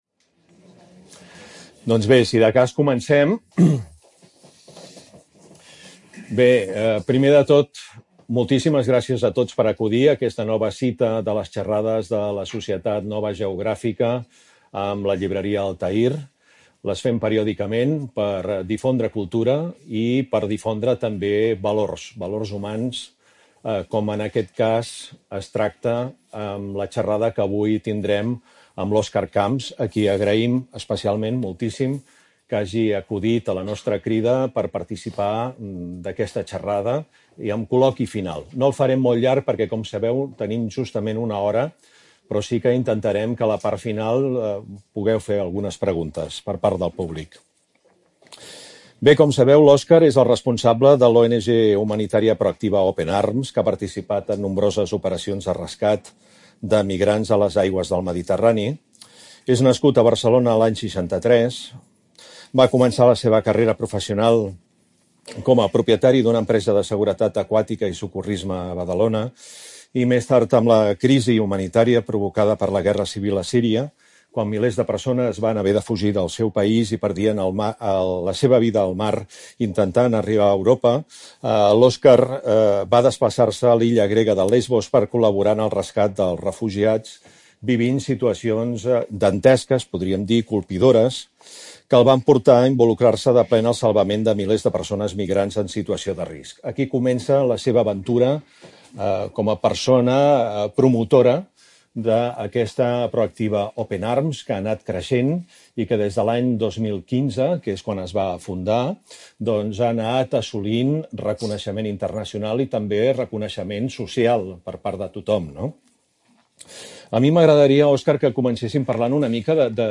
Les xerrades de la Societat Nova Geogràfica a la llibreria Altaïr estan obertes al públic i promouen el debat, el coneixement i la reflexió sobre diversos temes de l’actualitat de la geografia humana, cultural i mediambiental del nostre i del món.